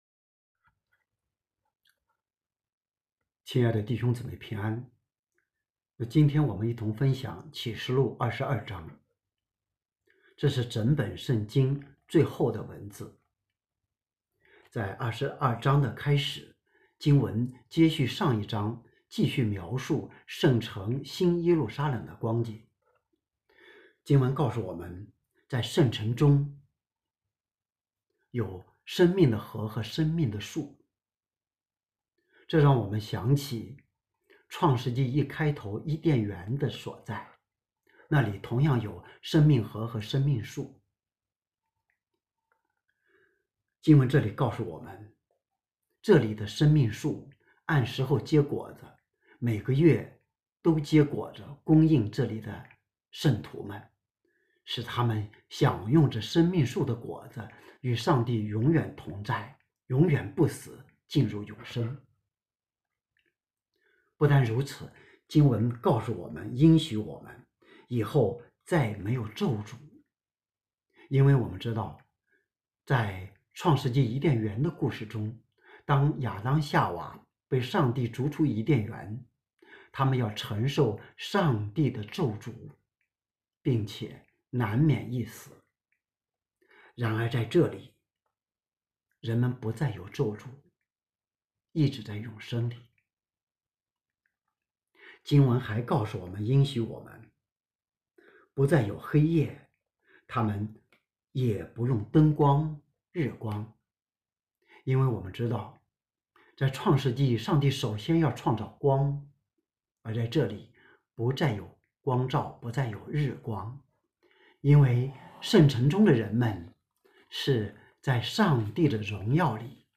北京基督教会海淀堂
《看哪，基督再来》 证道